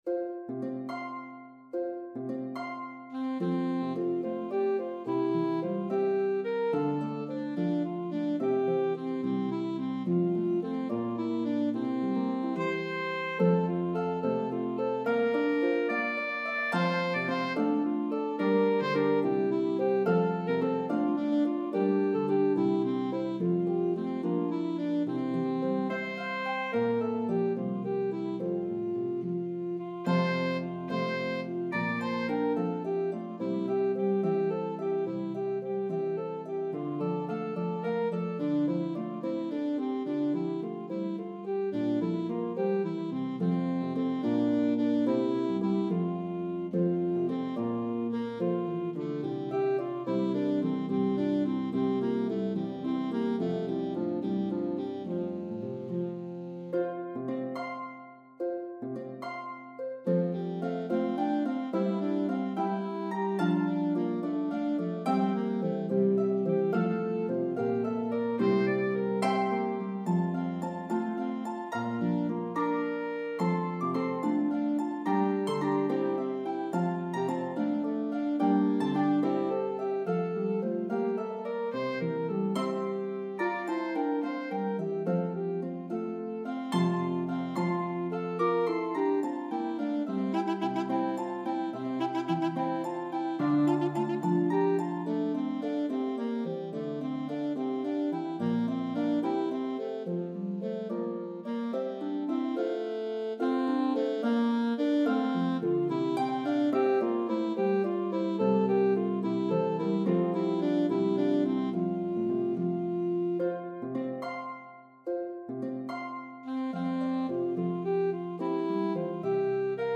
The Harp part is playable on either Lever or Pedal Harps.